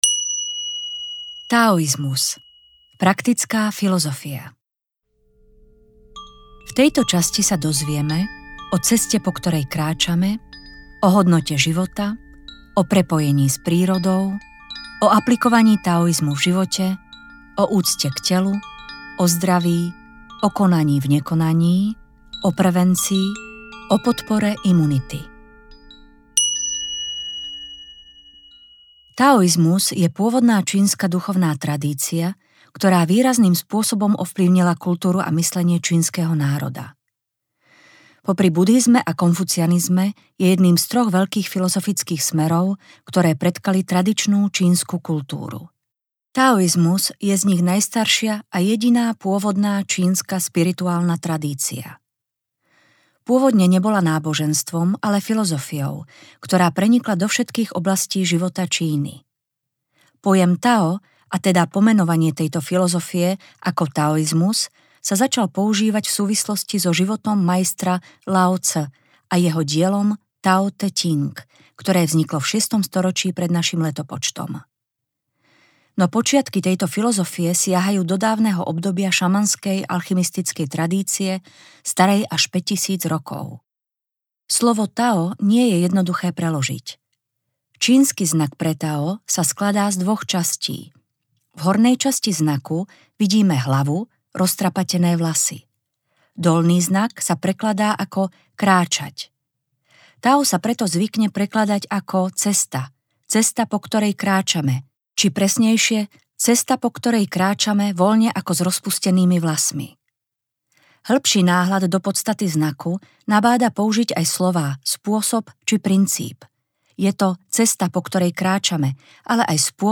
TAO - cesta ku zdraviu audiokniha
Ukázka z knihy